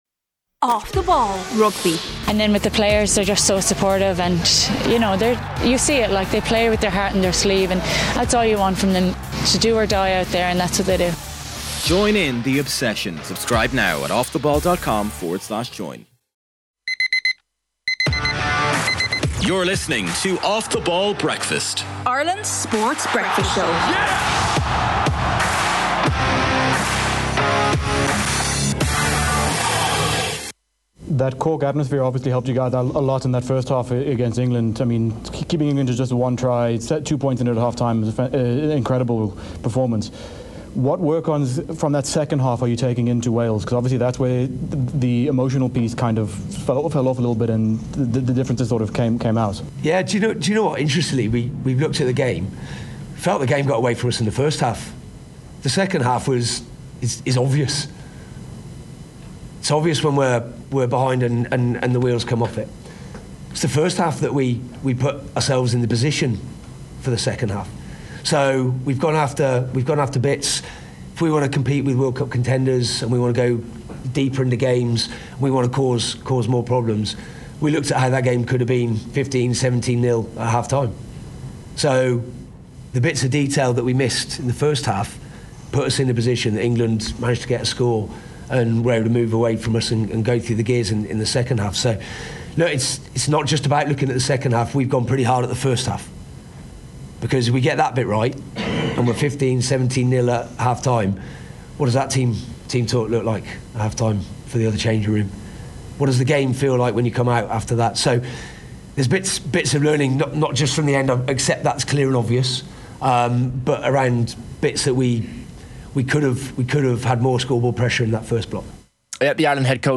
From legends interviews to live roadshows, analysis, depth charts, reviews, and weekly news.